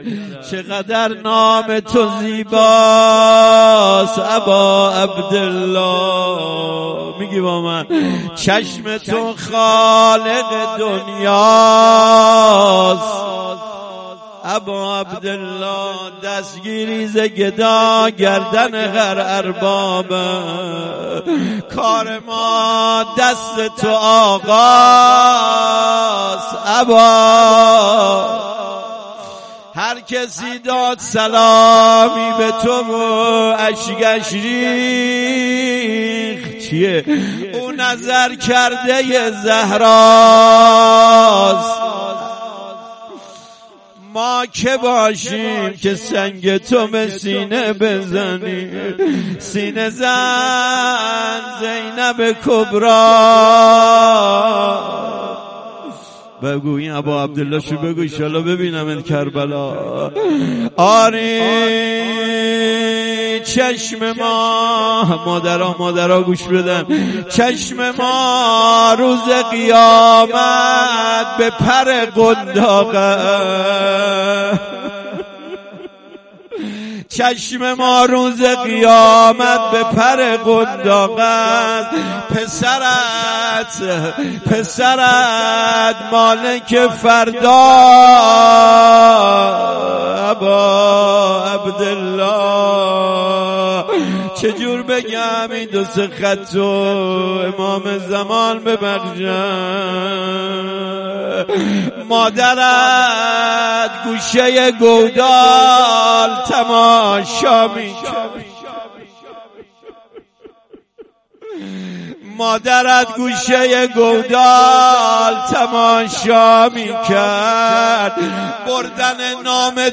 روضه.mp3